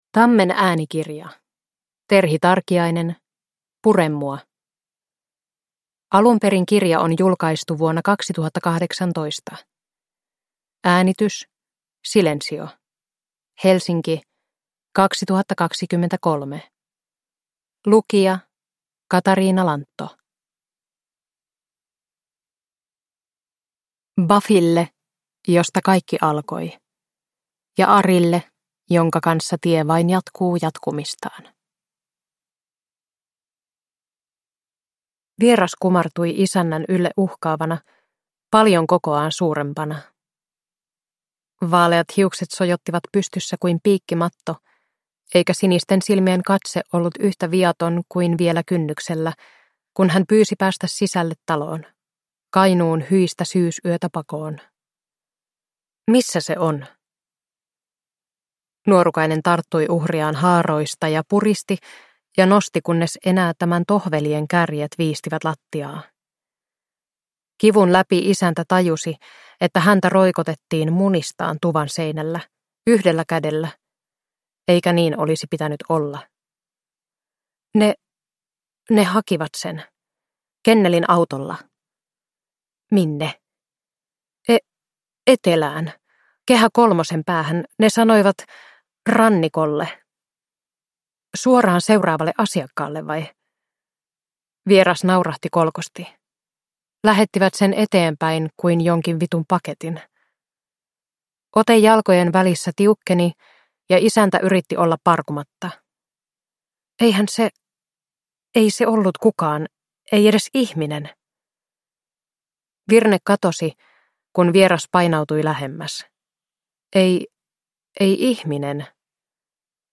Pure mua – Ljudbok – Laddas ner